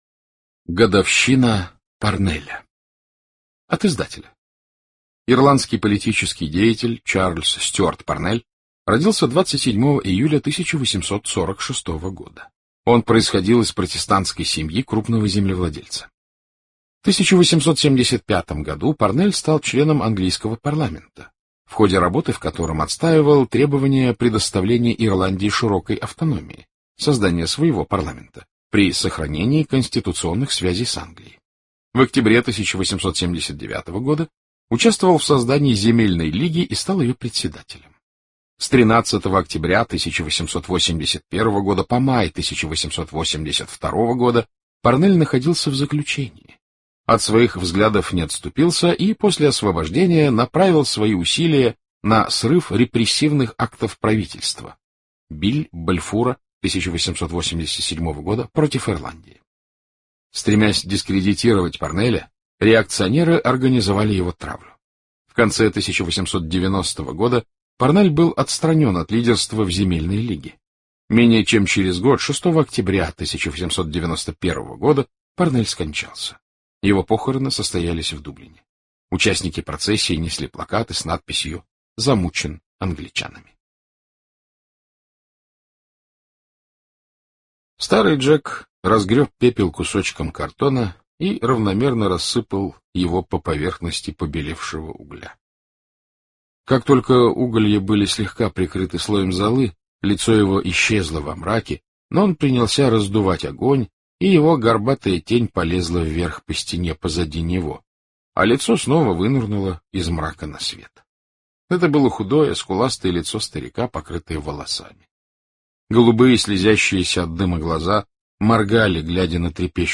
Годовщина Парнеля Джойс аудио книгу слушать, Годовщина Парнеля Джойс аудио книгу слушать бесплатно